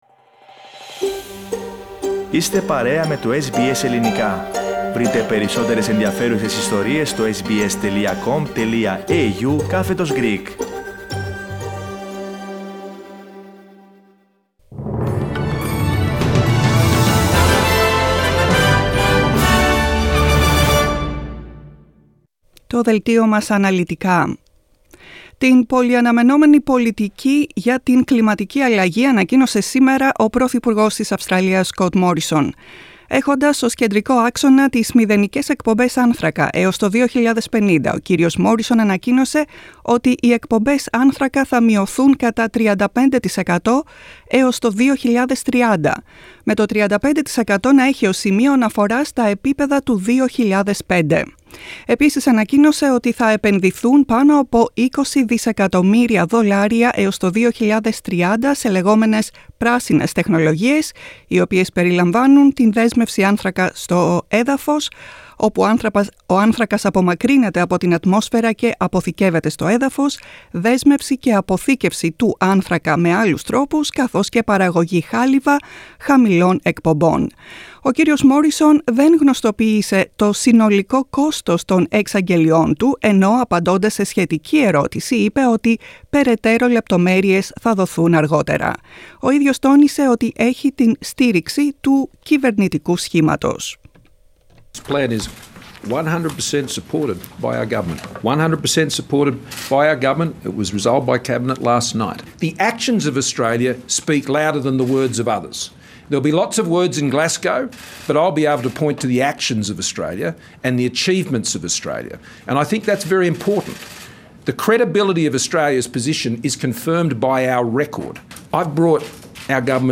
News in Greek, 26.10.21